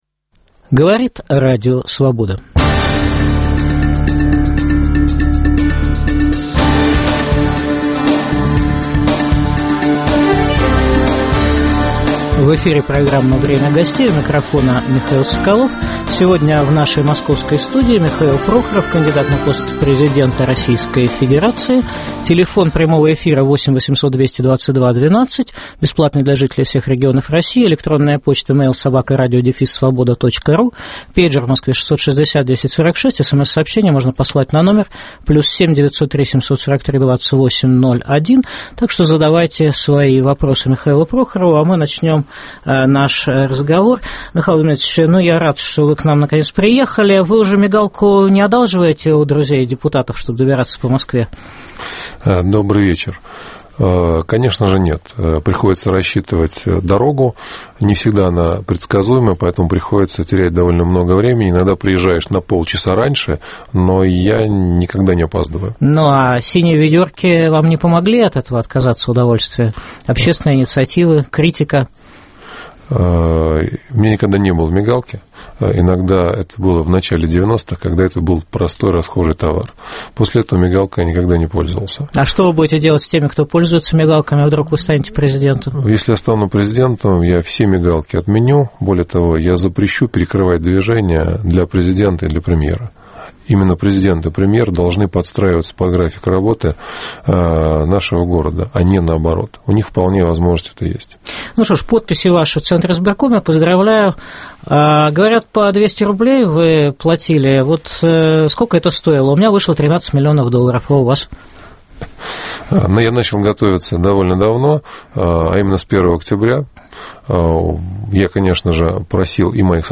Михаил Прохоров: альтернатива системе Владимира Путина и "думским старцам"? В программе в прямом эфире выступит кандидат на пост президента России Михаил Прохоров.